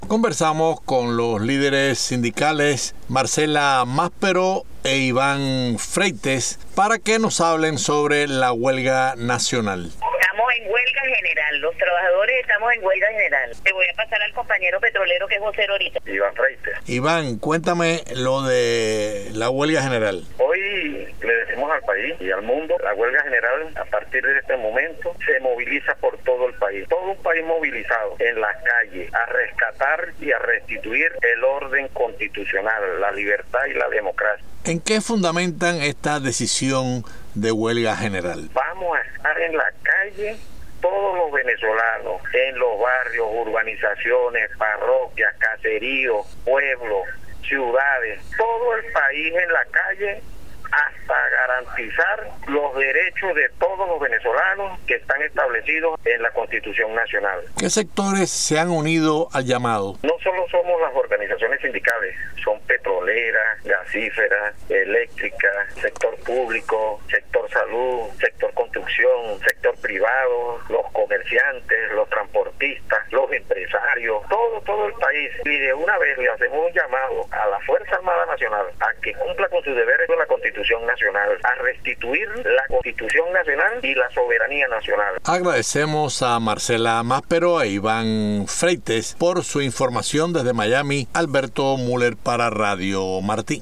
Entrevista
con dirigentes políticos venezolanos.